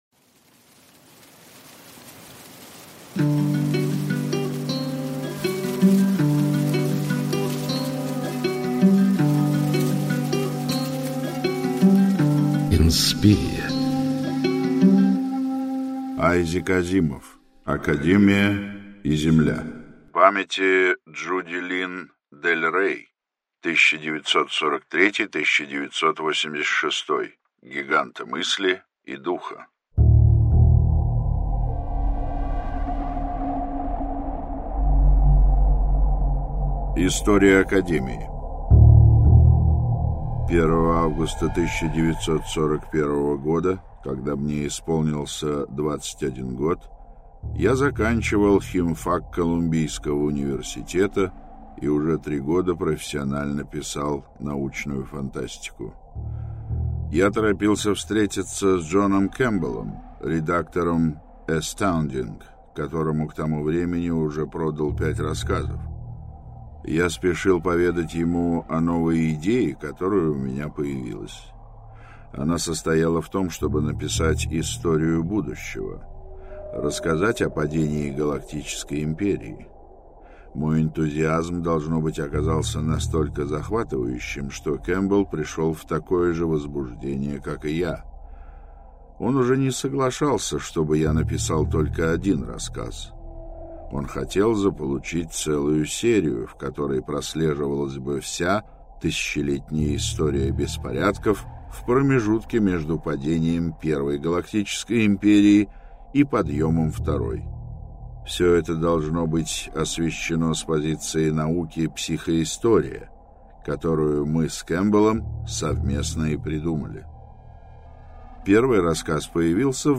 Аудиокнига Академия и Земля | Библиотека аудиокниг